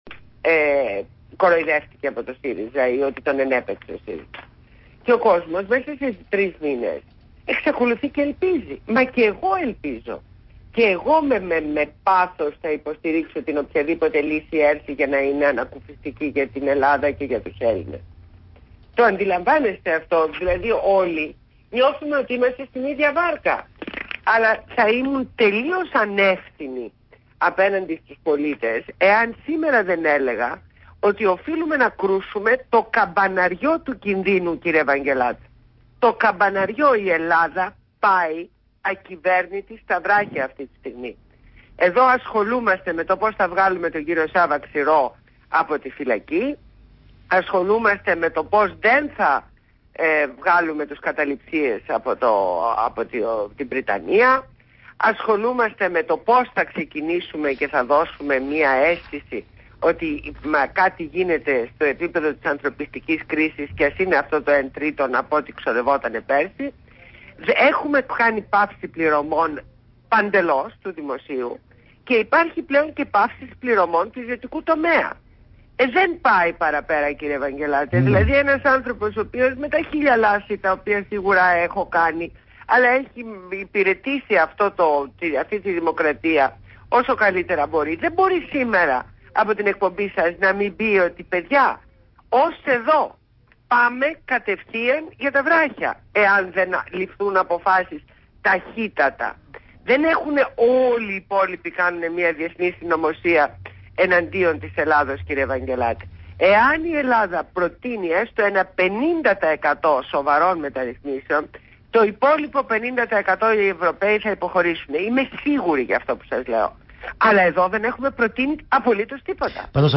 Συνέντευξη στο ραδιόφωνο Παραπολιτικά 90,1fm στην εκπομπή του Ν. Ευαγγελάτου.